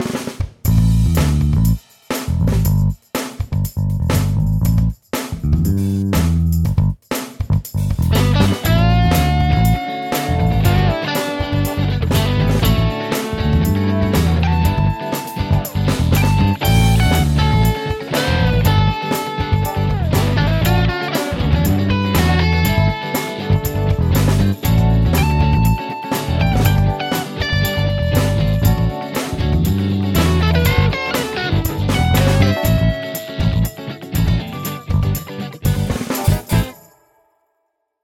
相変わらずノイジーだけどクリーン系は結構好みの音。
ベースはサンプリング音源をこれとコンプに通しただけ。ギターはこれ以外のエフェクトは使ってないです。
それにしてもIndependence Freeのタンバリンが良すぎ。